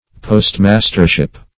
Postmastership \Post"mas`ter*ship\, n.